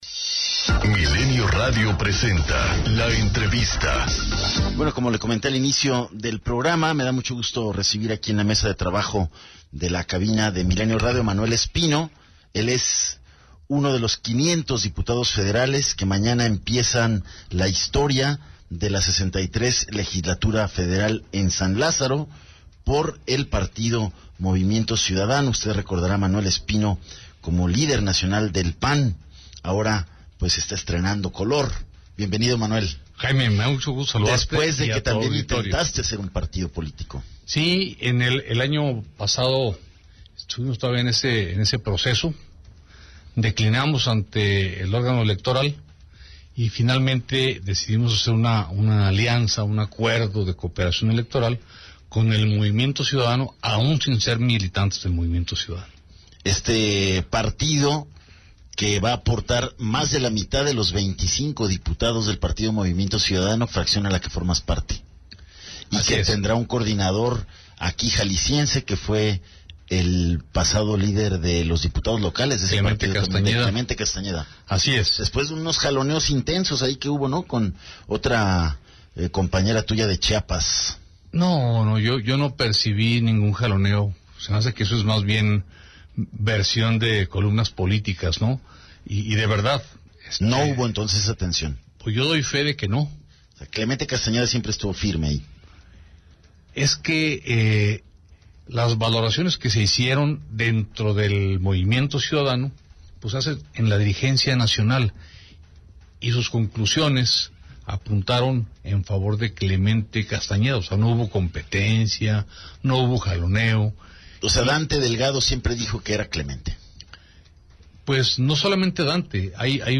ENTREVISTA 310815